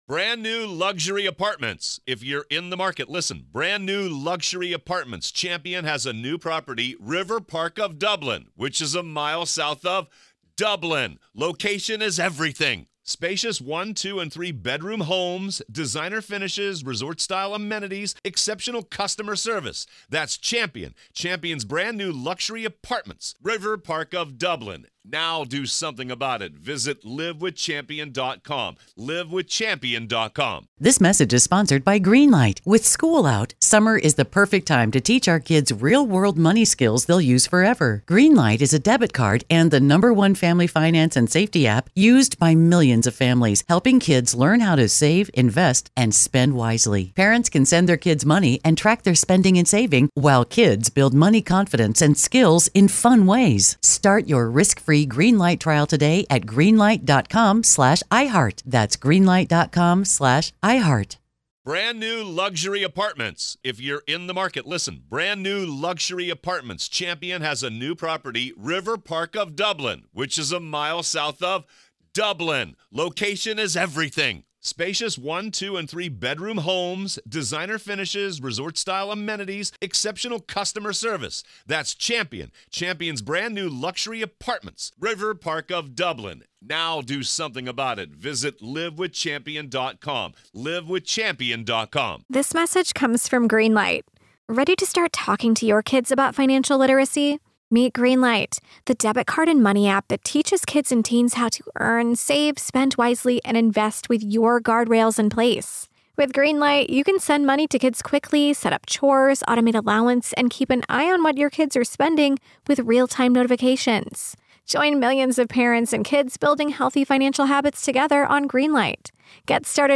joins hands with a rotating roster of guests, sharing their insights and analysis on a collection of intriguing